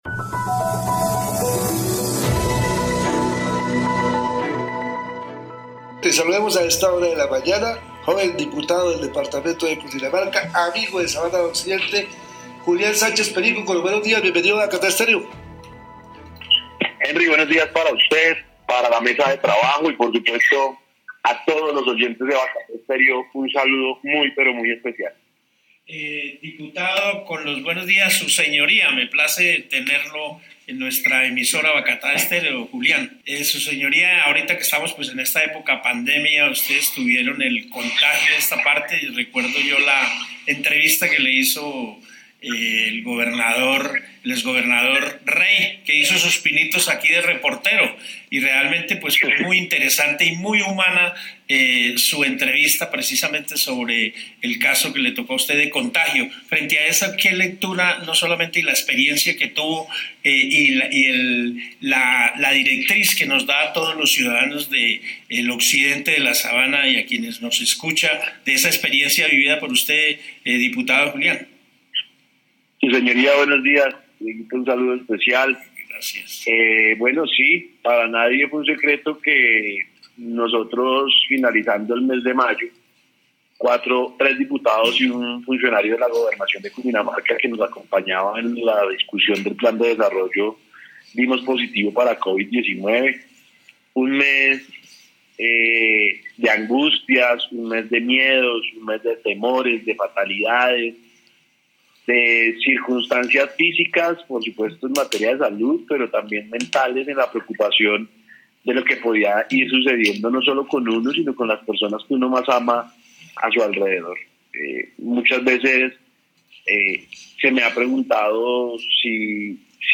Hoy en el programa Buenos Días Occidente, el diputado Julián Sánchez Perico comentó su experiencia tras ser diagnosticado junto con otros dos diputados y un funcionario de la Gobernación de Cundinamarca, como portador del virus Covid-19.